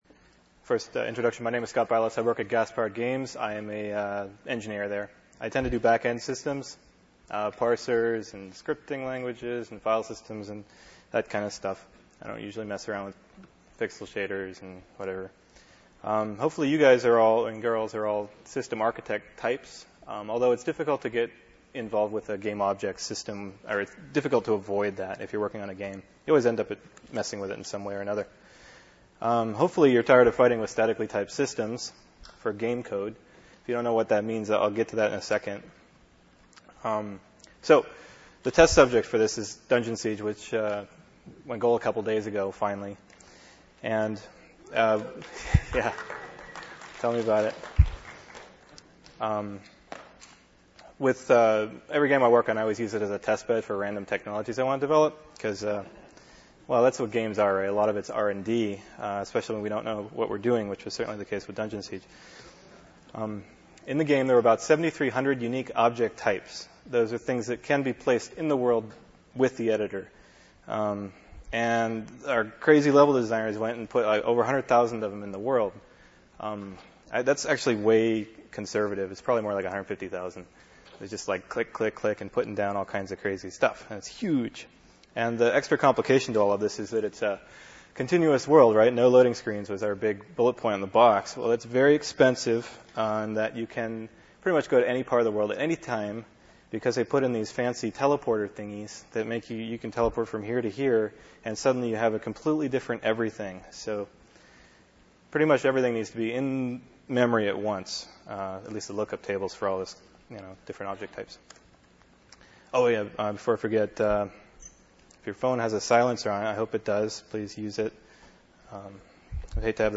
A Data-Driven Game Object System (lecture) Game Developers Conference, San Jose, 2002 This lecture was the inspiration for many game engines.